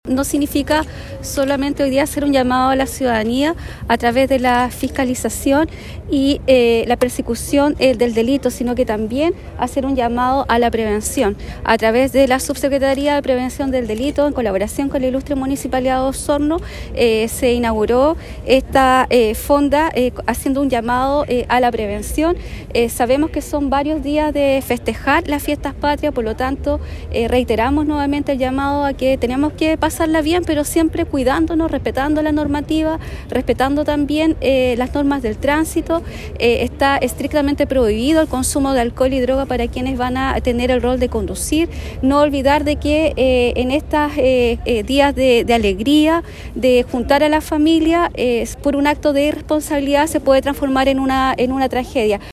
En la actividad, participó la Delegada Presidencial Provincial de Osorno, Claudia Pailalef, quien agregó que este 2023, son muchos días festivos por lo que se debe mantener el respeto irrestricto a todas las normativas vigentes.